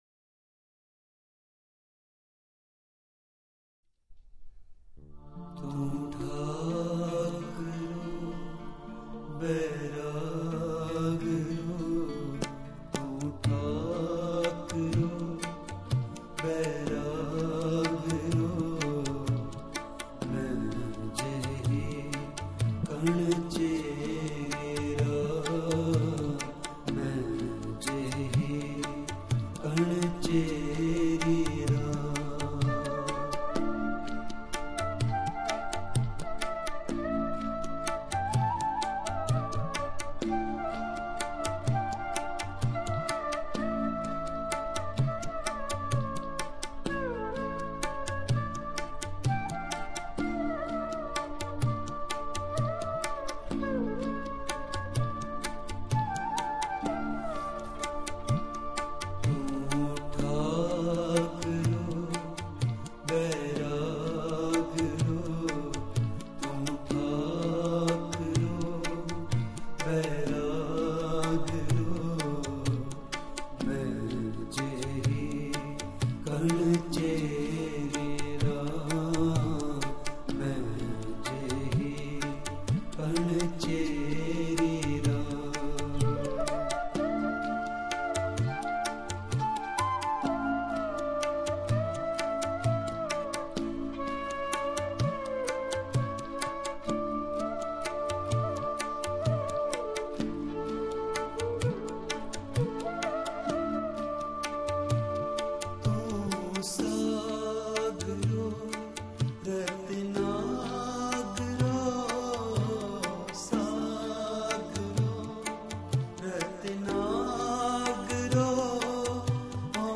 Raag Soohee, Chhant, Fifth Mehla, Third House: One Universal Creator God.